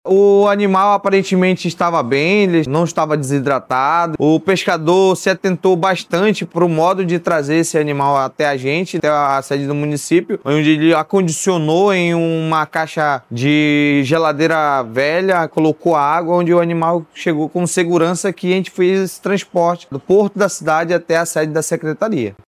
De acordo com o secretário municipal de Meio Ambiente de Itacoatiara, Leonardo Rocha, o animal foi levado em segurança até a sede do município.